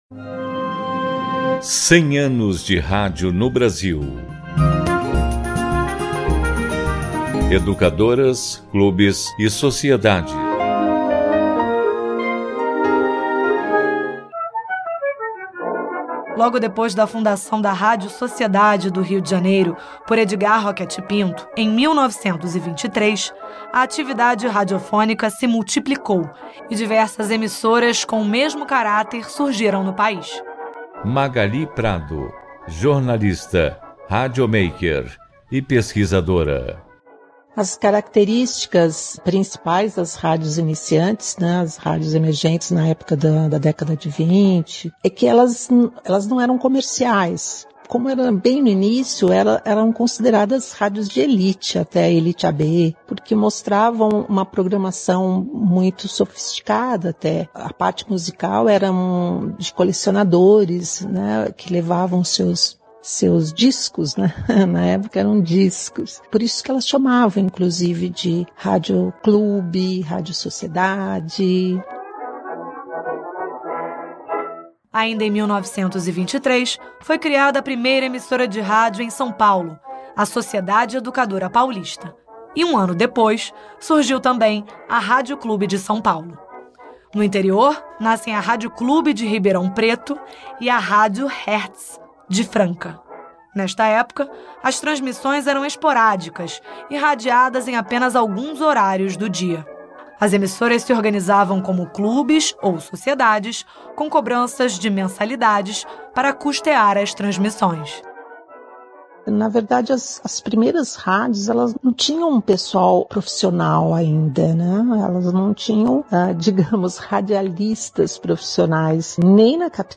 Até 7 de setembro, a Rádio MEC vai produzir e transmitir, diariamente, interprogramas com entrevistas e pesquisas de acervo sobre diversos aspectos históricos relacionados ao veículo.